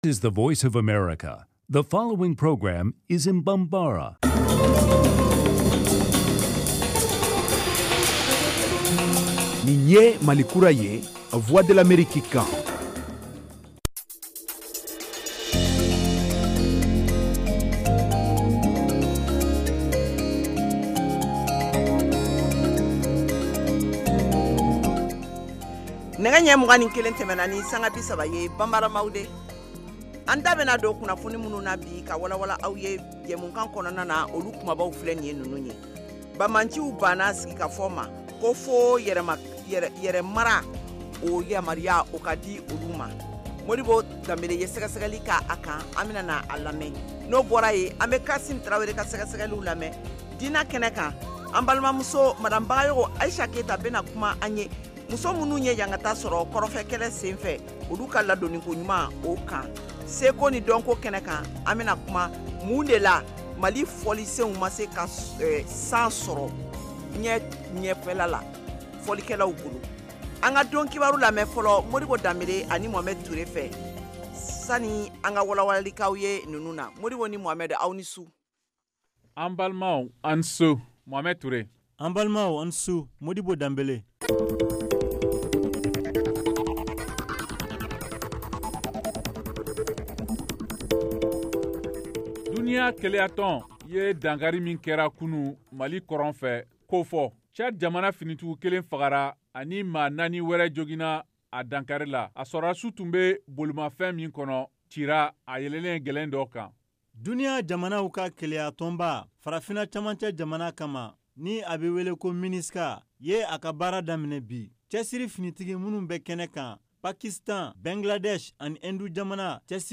Emission quotidienne en langue bambara
en direct de Washington